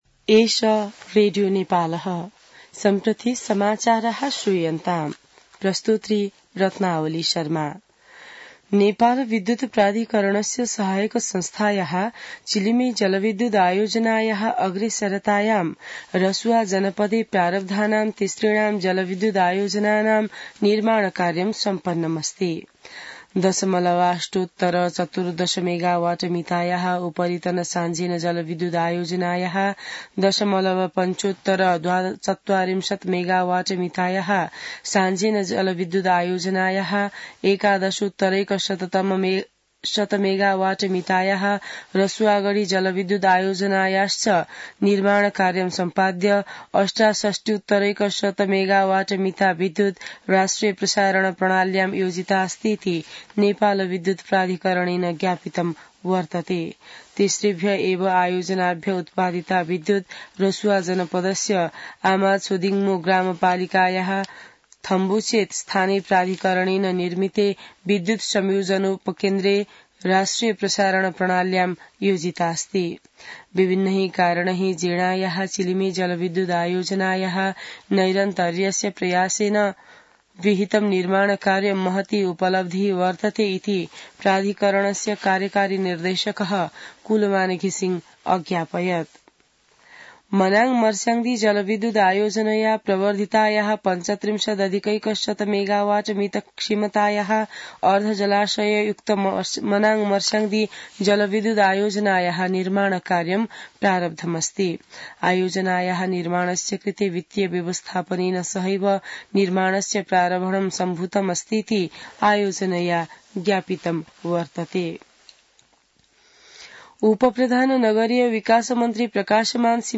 संस्कृत समाचार : १० मंसिर , २०८१